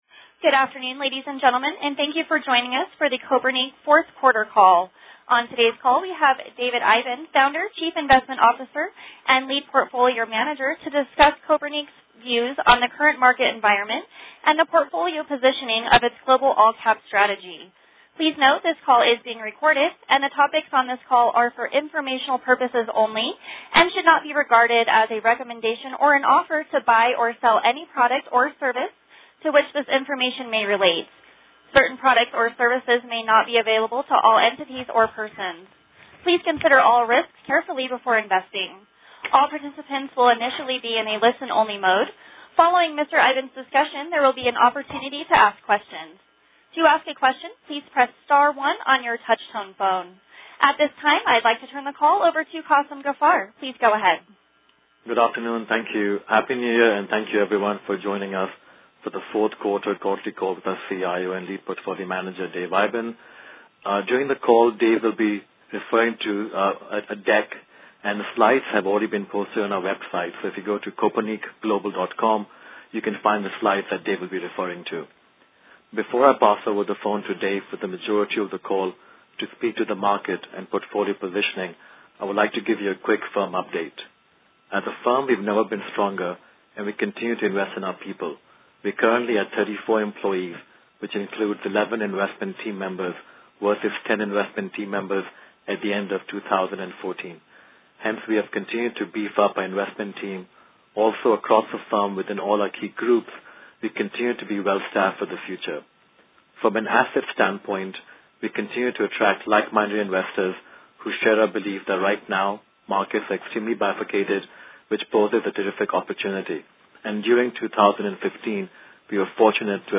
Q4 2015 Conference Call - Kopernik Global Investors